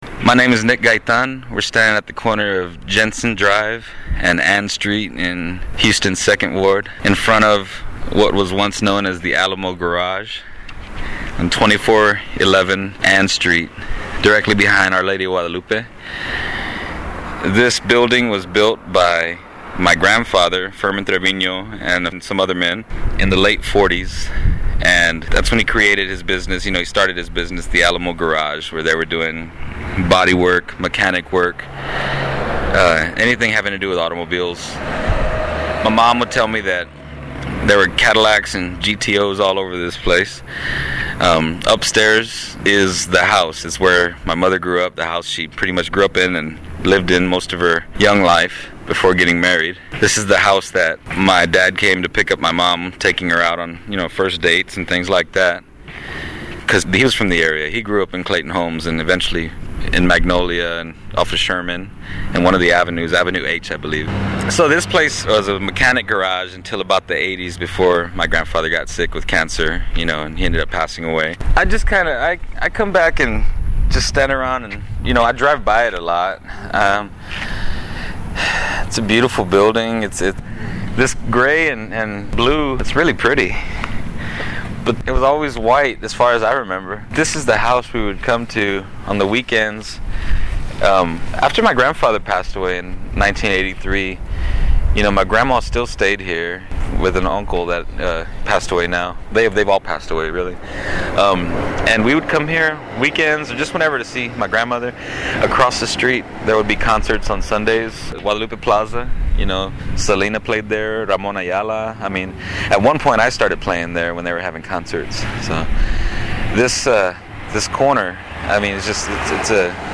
From 2411 Ann St to El Alacrán back to Our Lady of Guadalupe Church